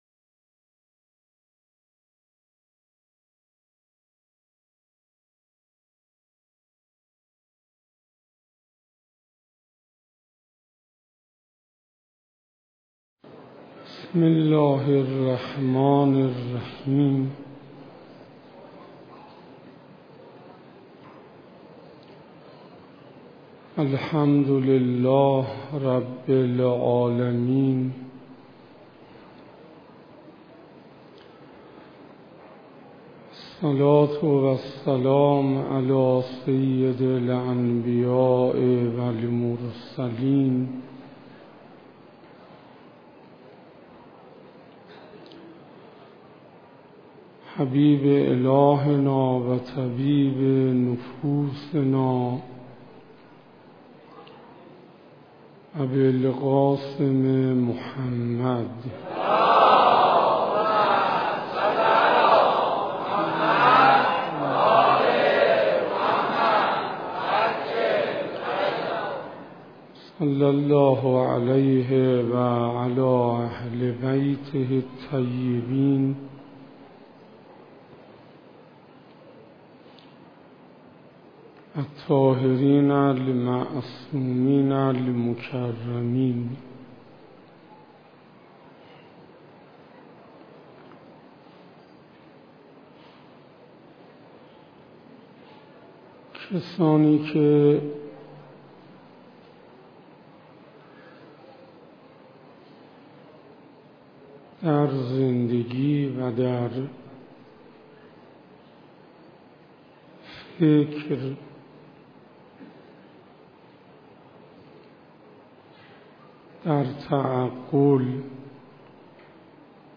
سخنرانی حجت الاسلام انصاریان